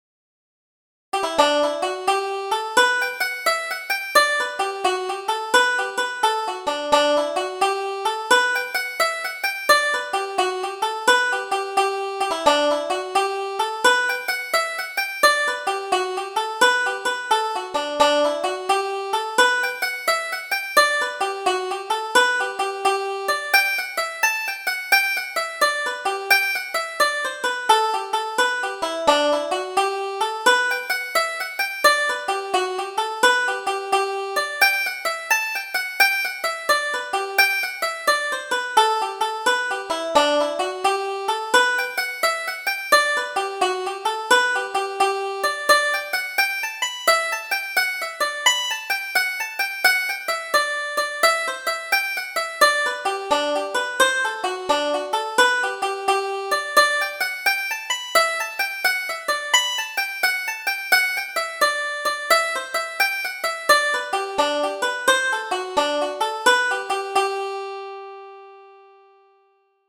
Double Jig: Katie's Fancy